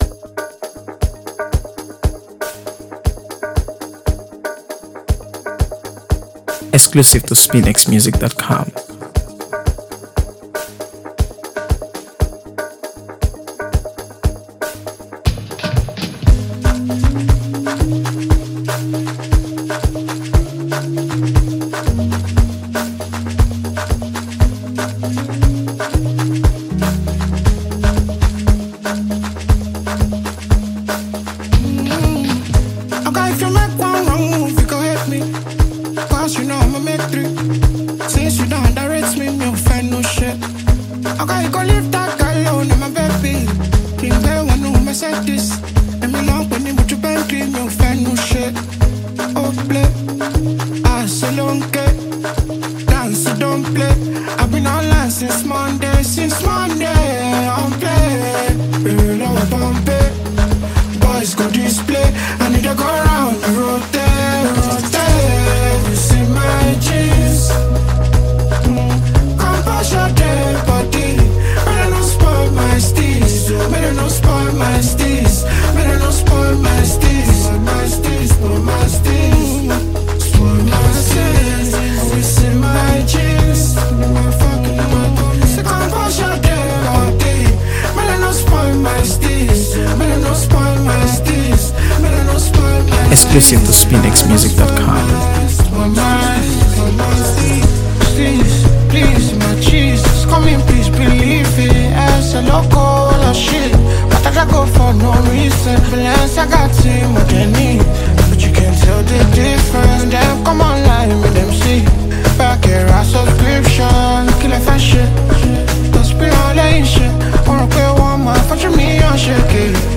AfroBeats | AfroBeats songs